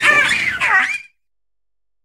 Cri de Tapatoès dans Pokémon HOME.